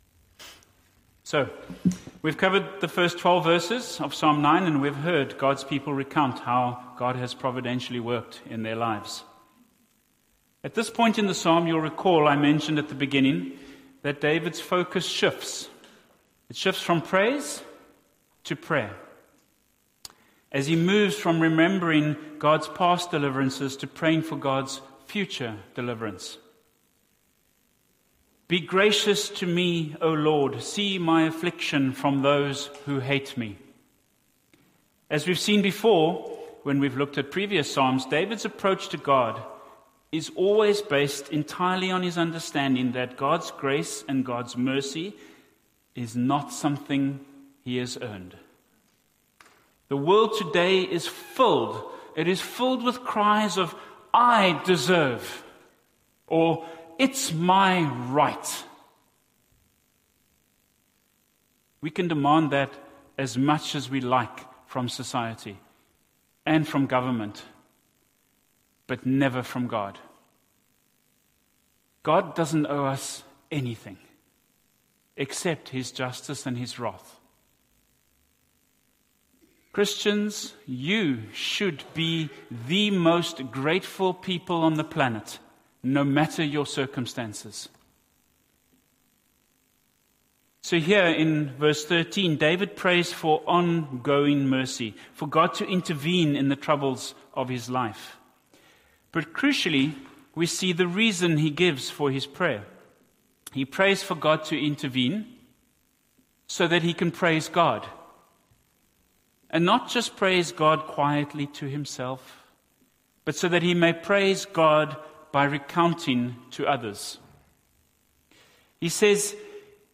Sermon Series: The Psalms